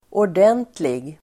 Uttal: [år_d'en:tlig]